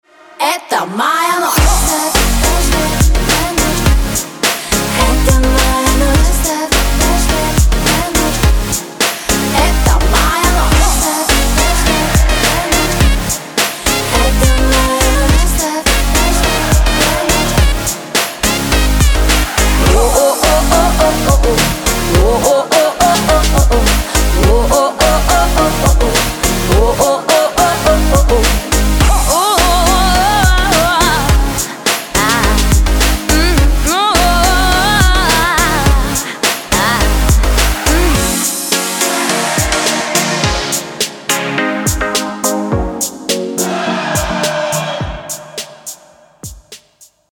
• Качество: 320, Stereo
женский вокал
dance
Electronic
future bass